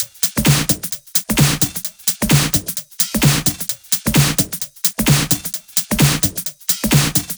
VFH2 130BPM Comboocha Kit 4.wav